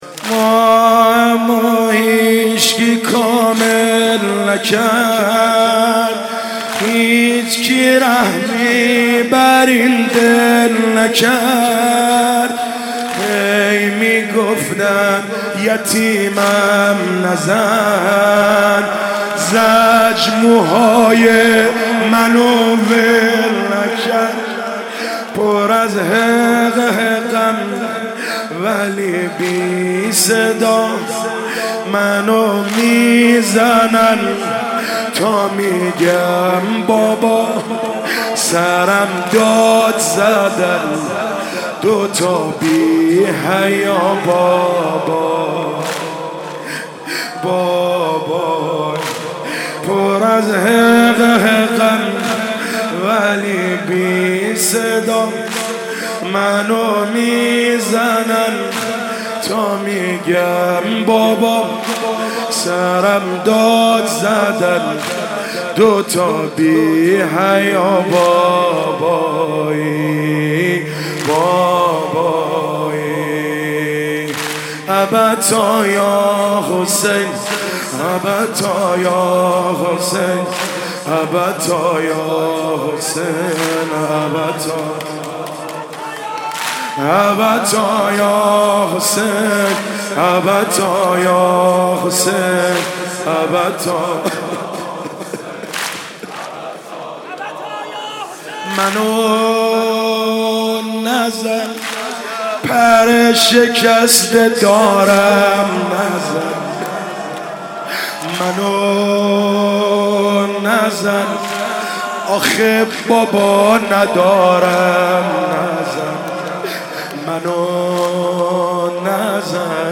شب سوم محرم _واحد _ماهمو هیچ کی کامل نکرد
مداحی